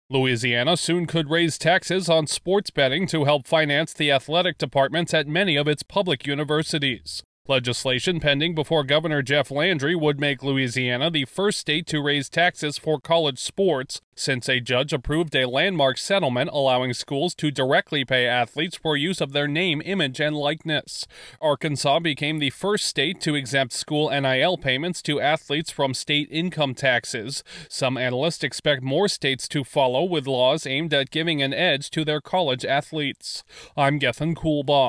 More taxes on sports gambling could be coming to one southern state to help support local college athletics. Correspondent